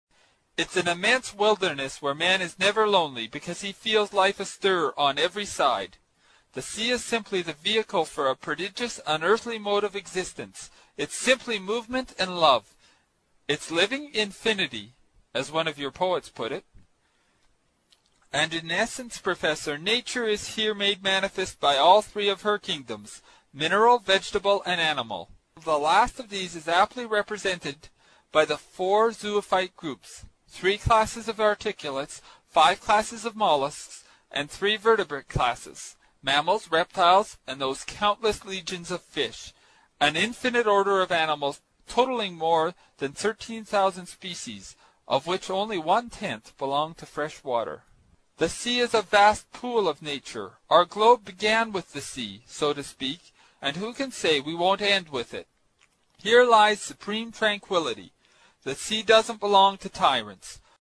英语听书《海底两万里》第148期 第10章 水中人(19) 听力文件下载—在线英语听力室
在线英语听力室英语听书《海底两万里》第148期 第10章 水中人(19)的听力文件下载,《海底两万里》中英双语有声读物附MP3下载